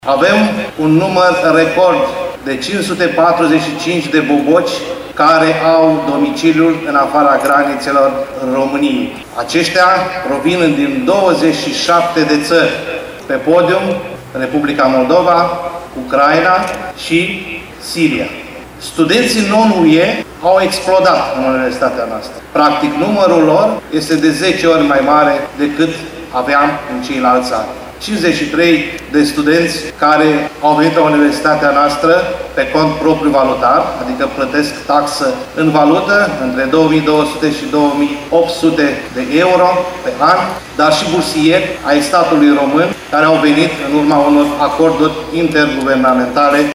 După ce i-a felicitat pe cei 4 mii de boboci, rectorul VALENTIN POPA a declarat, la festivitatea de deschidere, că oferta educațională internațională este tot mai consistentă.